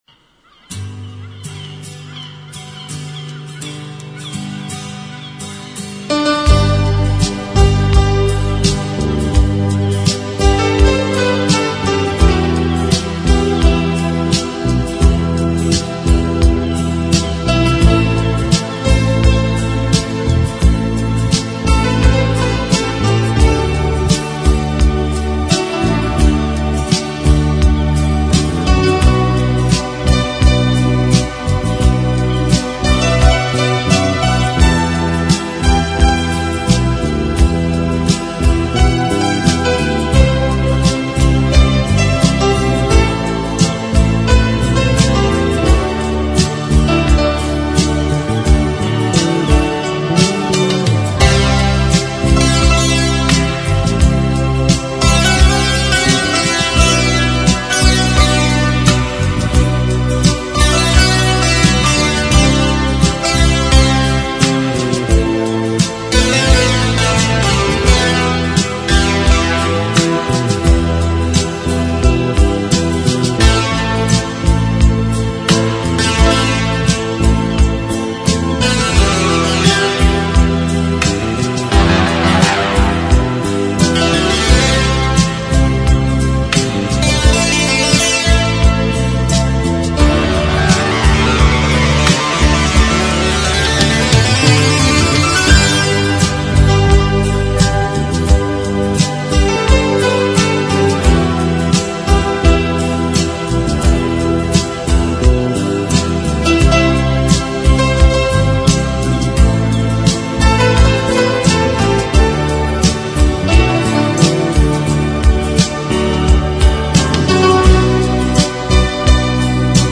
Entre-Instrumentais